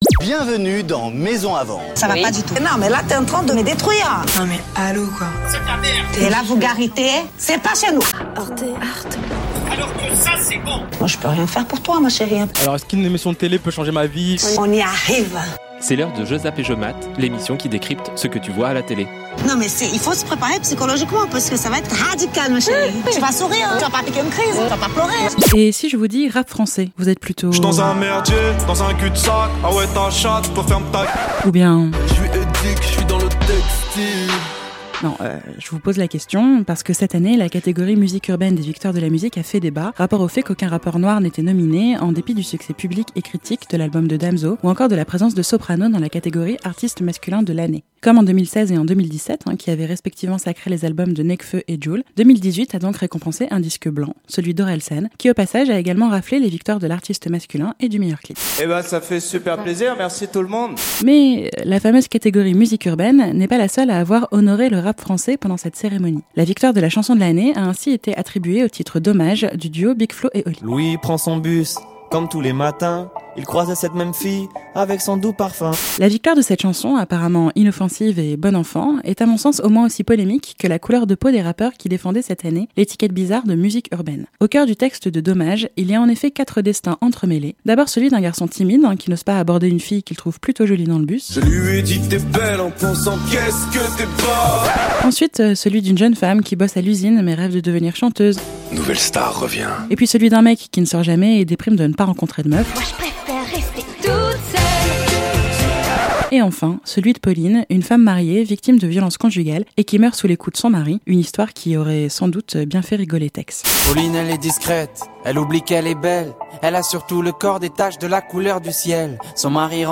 Cette semaine, je zappe et je mate le clip de Bigflo et Oli, "Dommage", un morceau de rap récemment promu "Meilleure chanson de l'année" aux Victoires de la musique et qui tourne en boucle sur CSTAR. Sous ses dehors inoffensifs et bon enfant, le texte du duo ultra-populaire auprès des ados se met en fait au service d'un agenda politique assez conservateur, que l'écriture du clip et sa mise en scène des violences conjugales ne font que renforcer. Décryptage en musique.